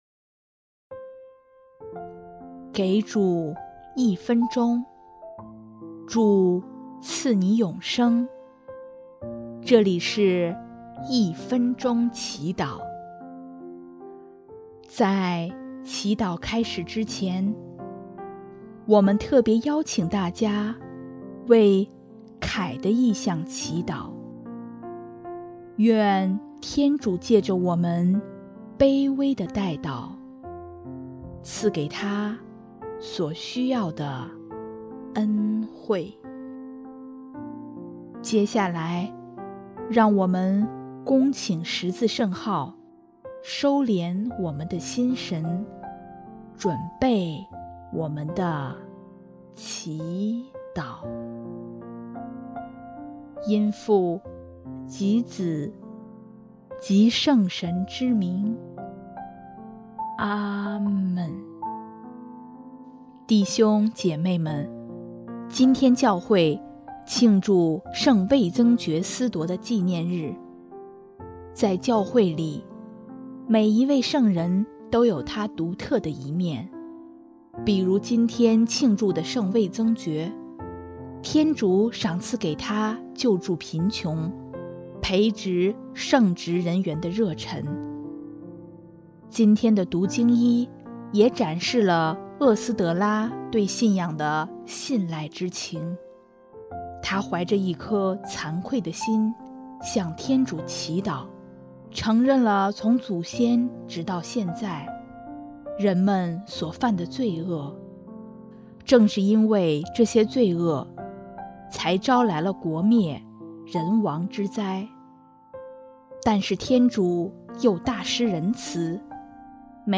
音乐：第三届华语圣歌大赛参赛歌曲《世界真的好美》